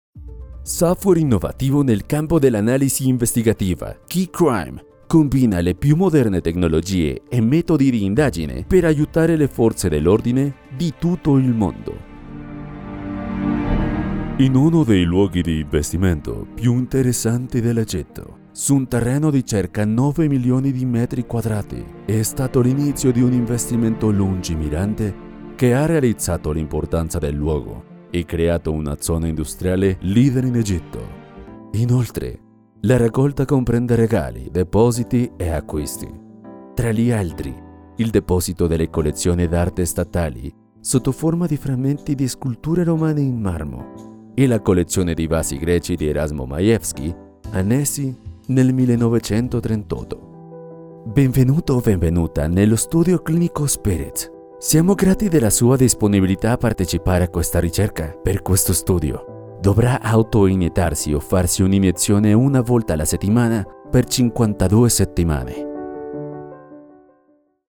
Artistes voix off italiens
Chaleureux
De la conversation
Amical